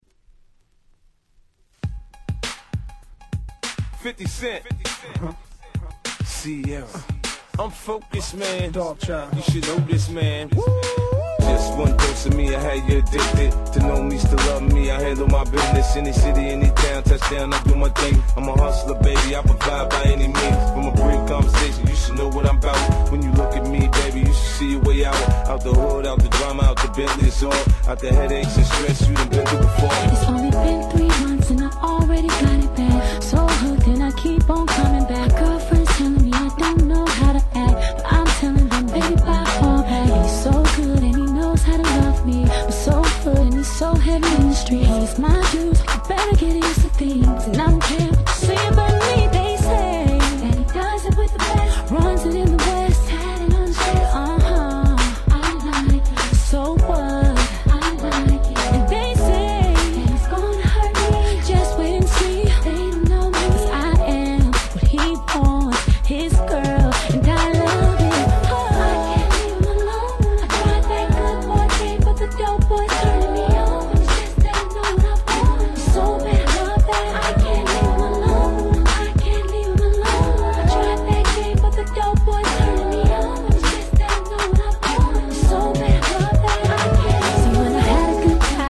06' Smash Hit R&B !!